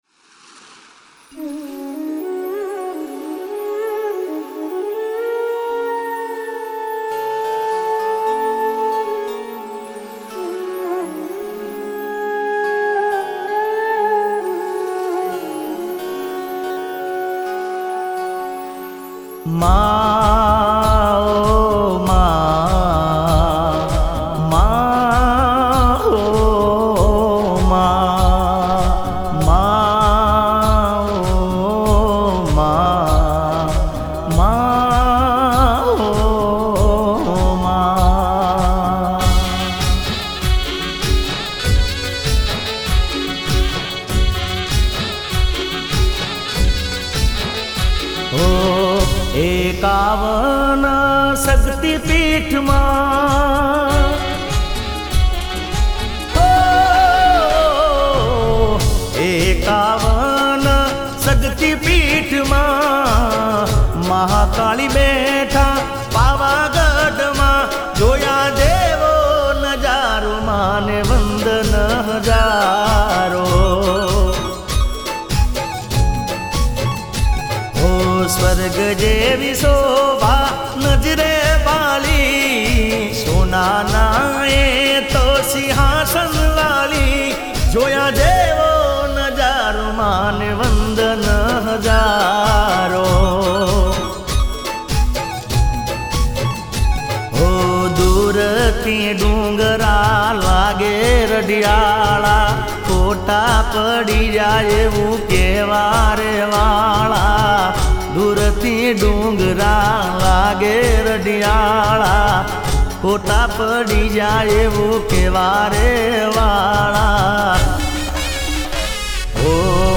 Category: Haryanvi Single Track Song